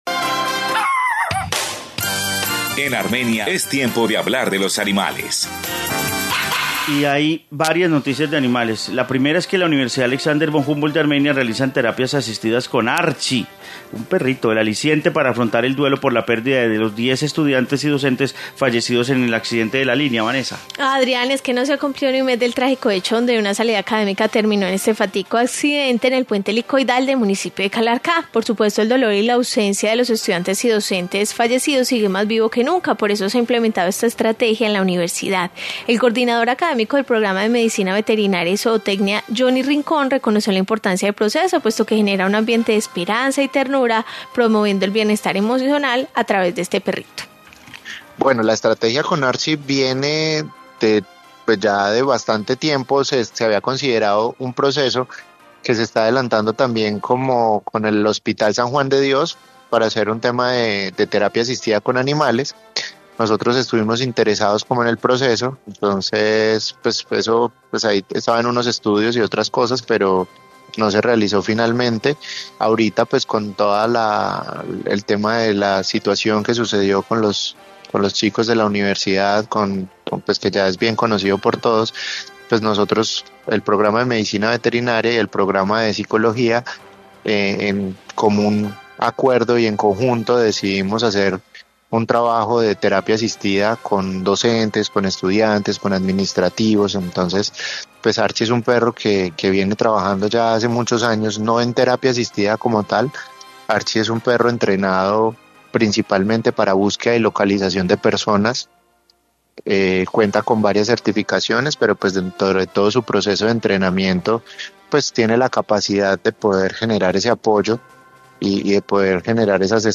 Informe sobre Archie